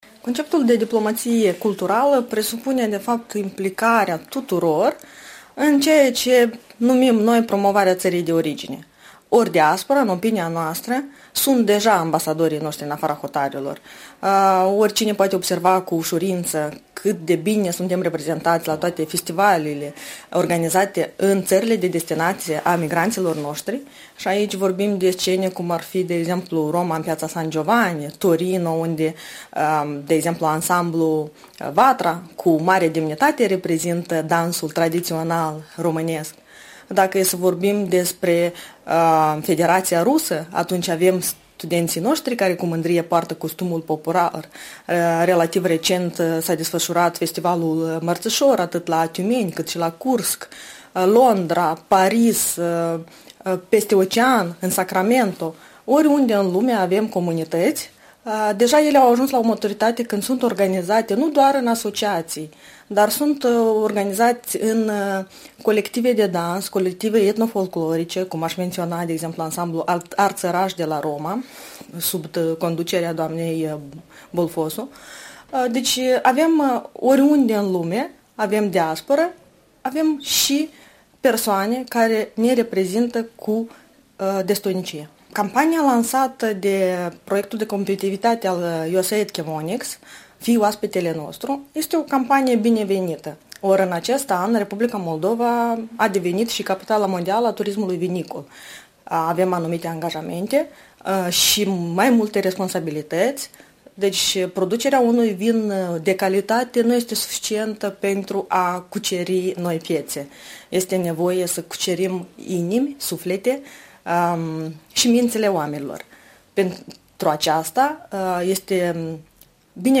Interviu cu Olga Coptu, şefa Biroului Relații cu Diaspora